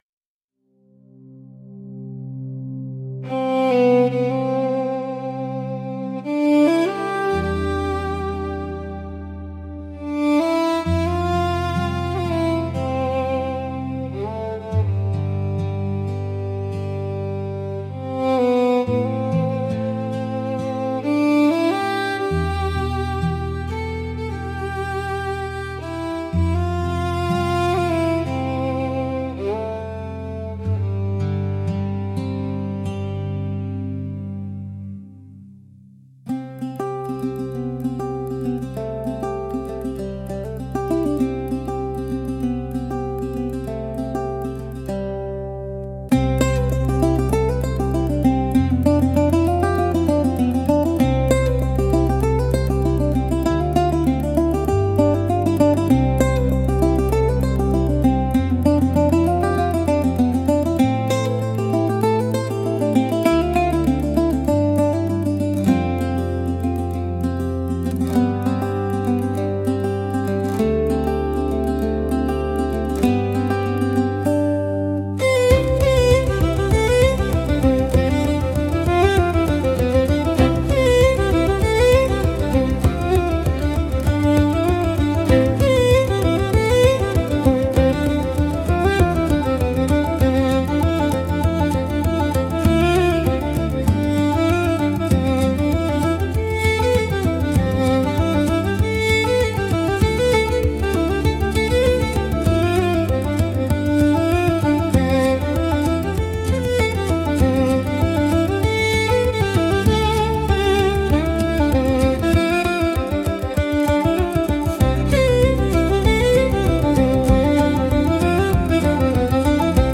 calm instrumental playlist